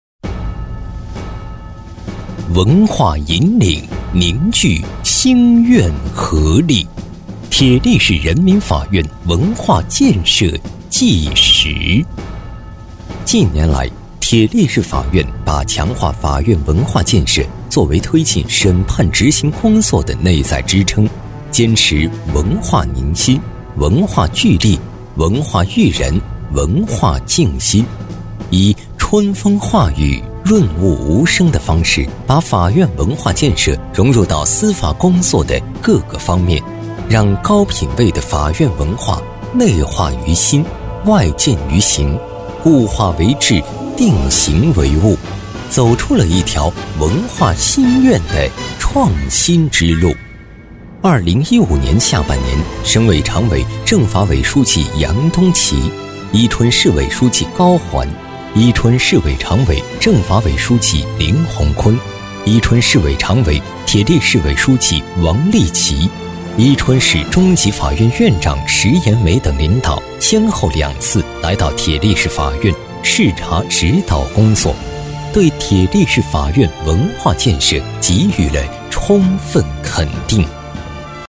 【专题】铁力市人民法院男73-磁性沉稳
【专题】铁力市人民法院男73-磁性沉稳.mp3